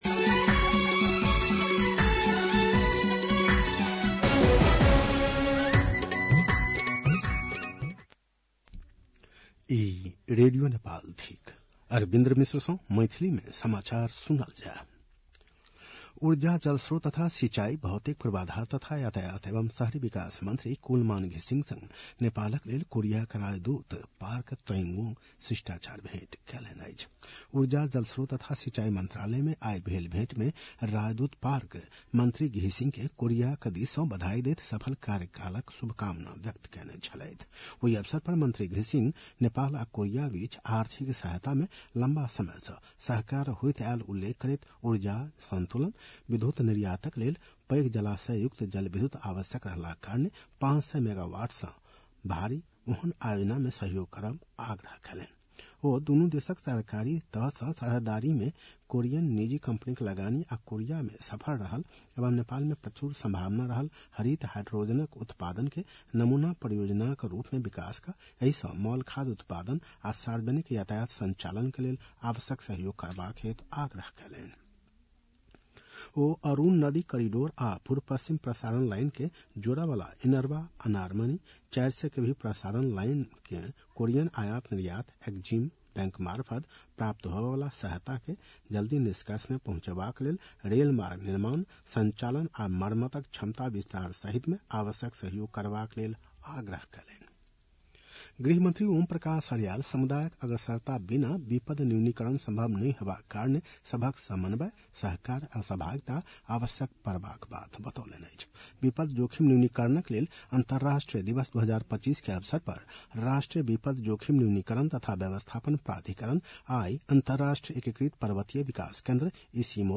मैथिली भाषामा समाचार : २७ असोज , २०८२
6-pm-maithali-news-6-27.mp3